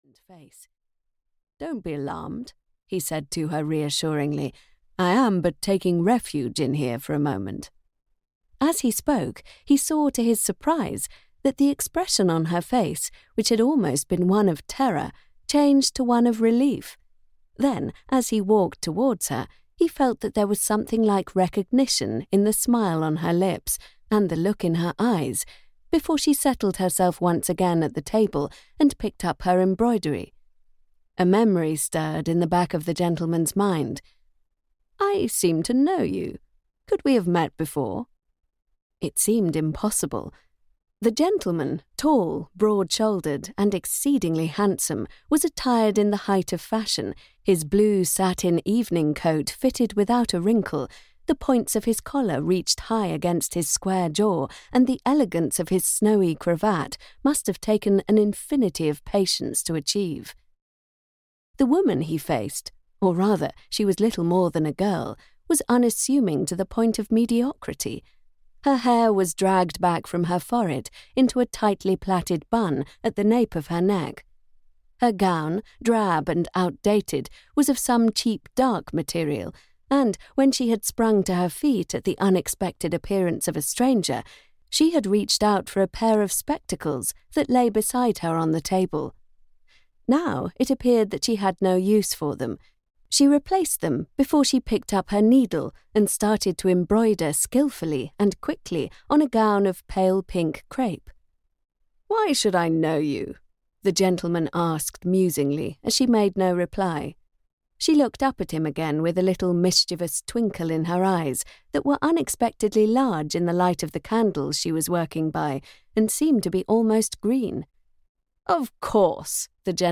The Audacious Adventuress (EN) audiokniha
Ukázka z knihy